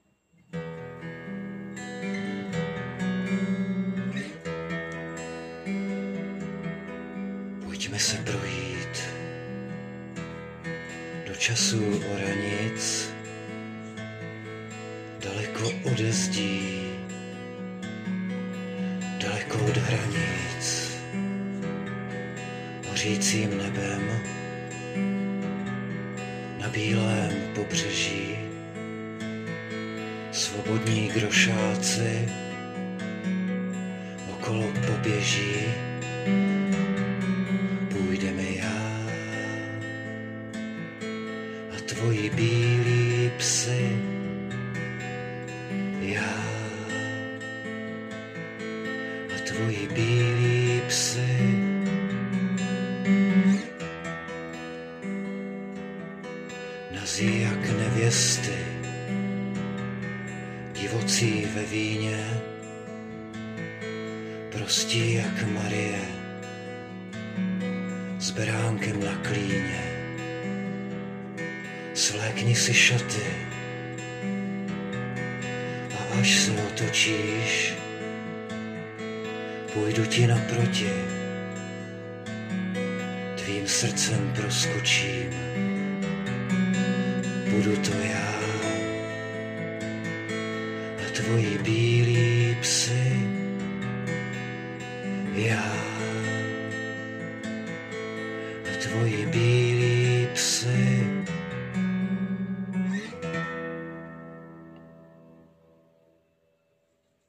Mám prostě ráda i jinde onu "jednoduchost",jen autor a kytara nebo jiný nástroj....není co skrýt,schovat někdy v "hlomozu"kapely/samozřejmě nemám nic proti kapelám,taky si
Pomezní proudy, jo, to je moje :) Mám teď konečně kytaru, na kterou je vážně radost hrát, poprvý v životě jsem si něco takovýho koupil a společně srůstáme.